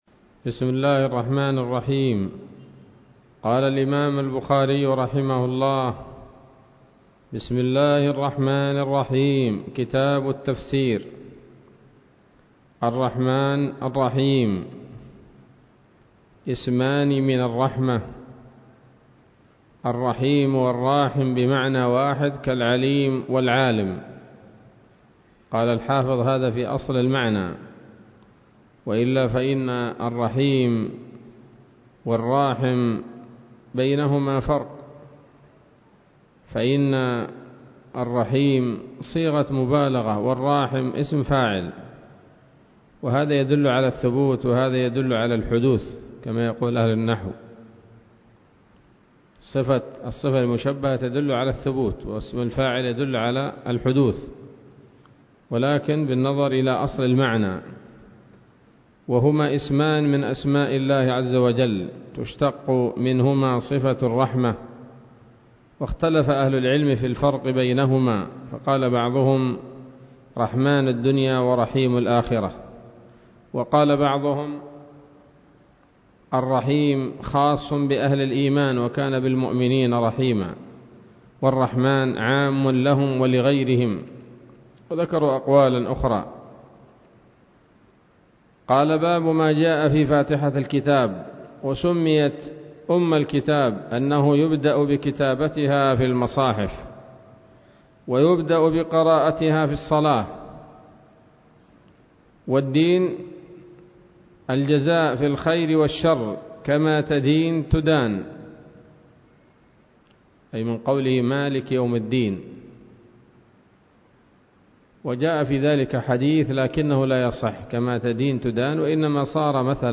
الدرس الأول من كتاب التفسير من صحيح الإمام البخاري